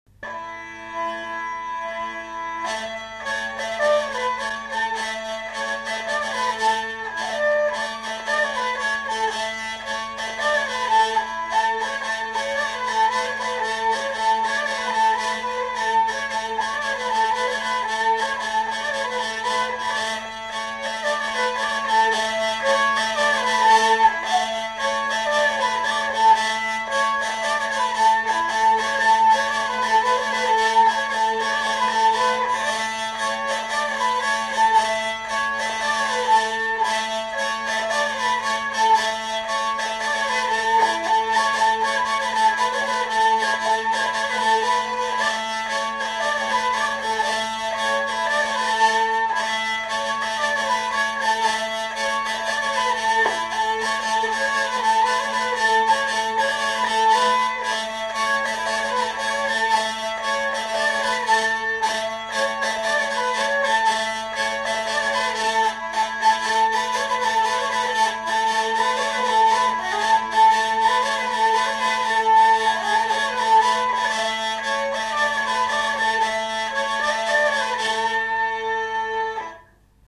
Aire culturelle : Gabardan
Lieu : Herré
Genre : morceau instrumental
Instrument de musique : vielle à roue
Danse : rondeau